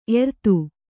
♪ ertu